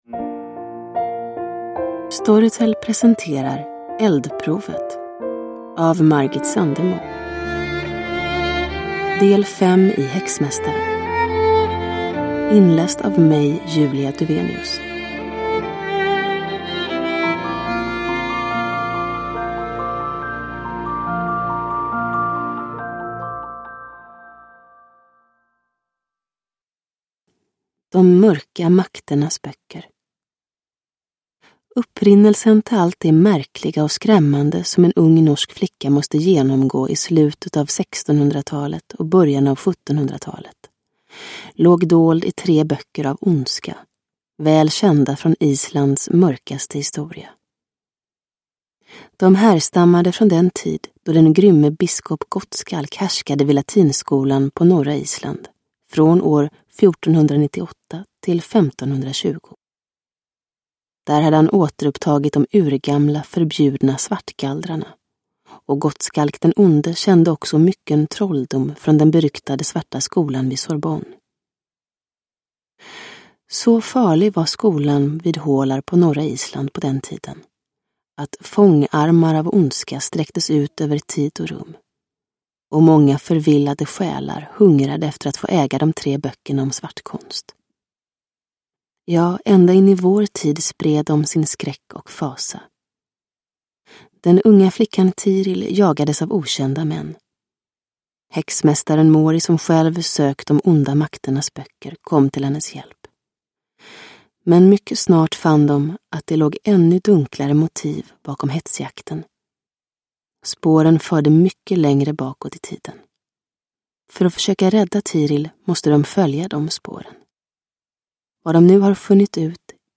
Eldprovet – Ljudbok – Laddas ner